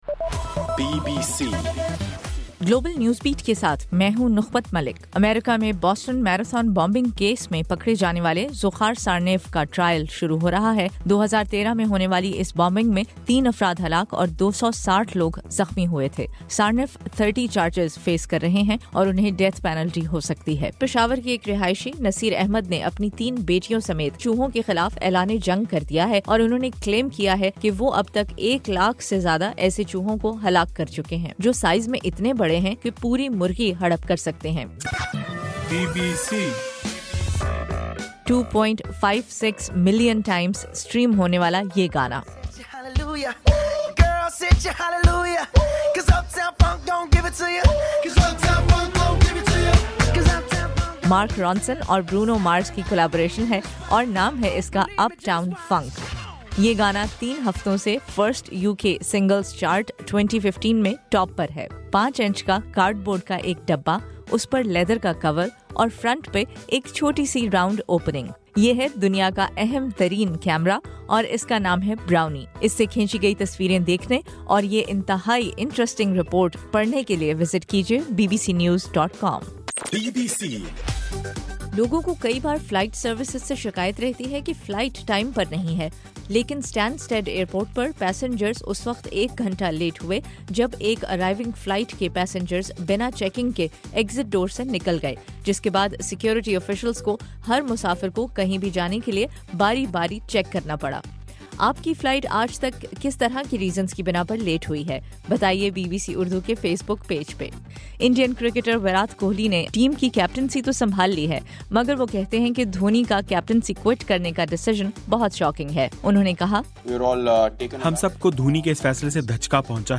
جنوری 5: رات 11 بجے کا گلوبل نیوز بیٹ بُلیٹن